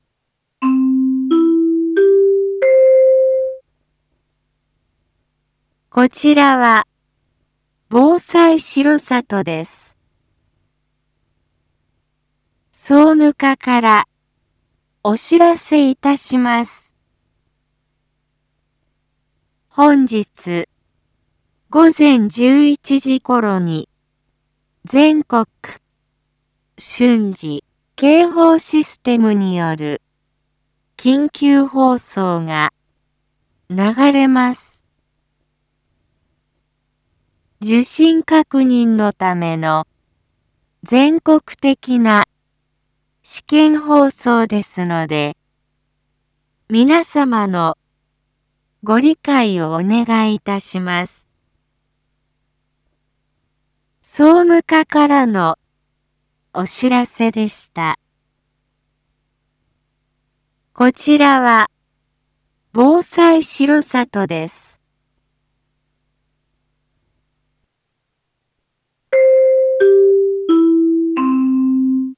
Back Home 一般放送情報 音声放送 再生 一般放送情報 登録日時：2023-11-15 07:01:13 タイトル：全国瞬時警報システムの訓練放送 インフォメーション：こちらは、防災しろさとです。